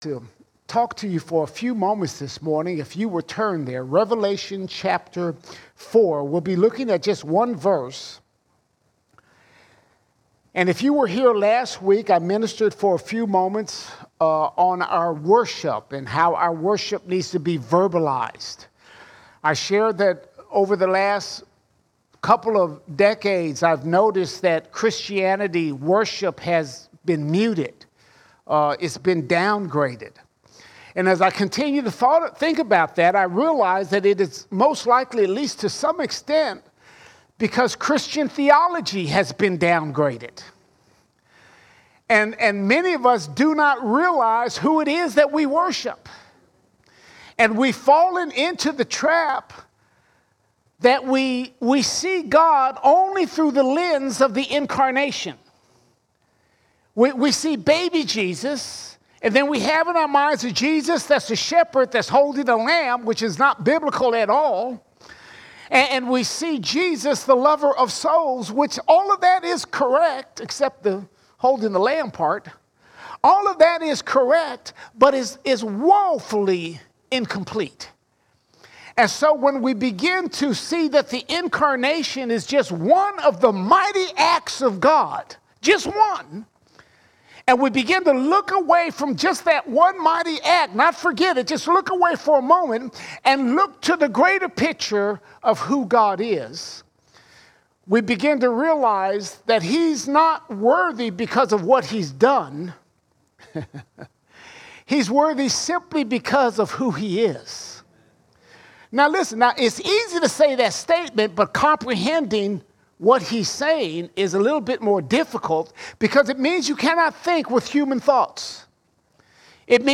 8 January 2024 Series: Sunday Sermons Topic: God is All Sermons Was and Is and Is To Come Was and Is and Is To Come The angels tell us of this amazing God who is beyond description.